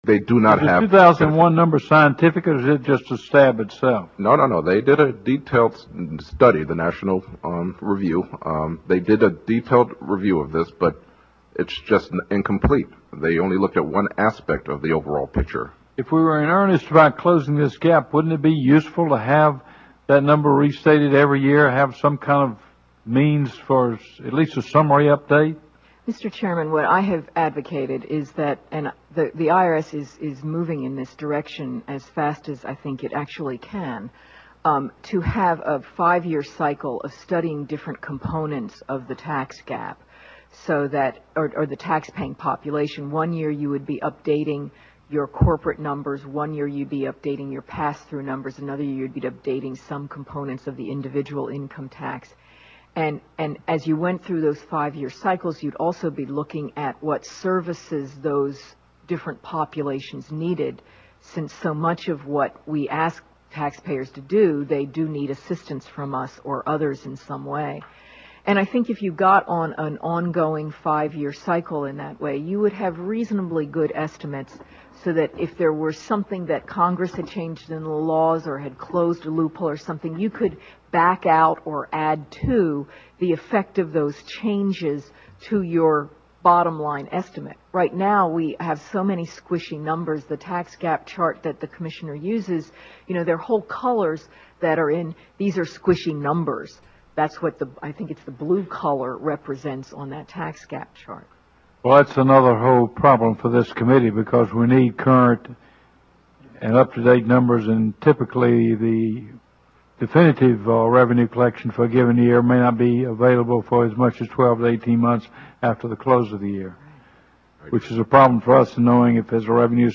Testimony of Mr. George, IG for Tax Administration, Treasury Dept. :